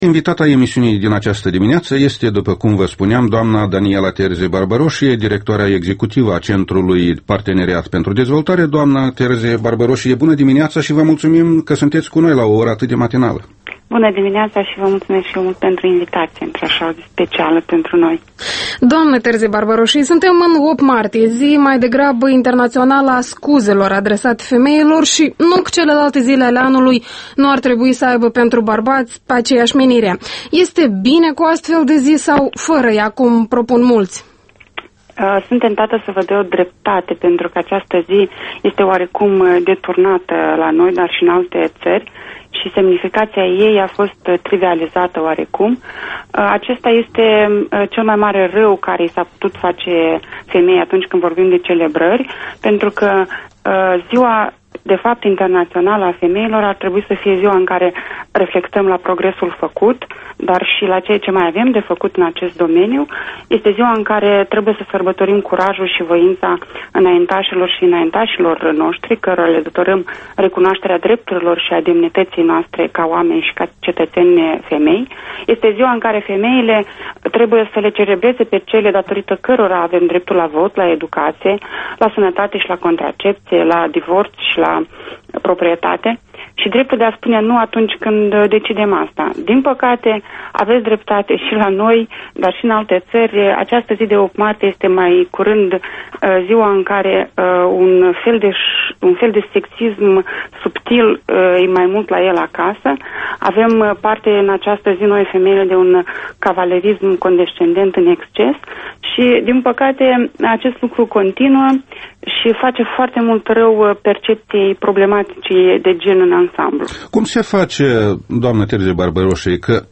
Interviu matinal EL